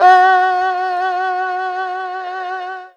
52-bi05-erhu-f-f#3.wav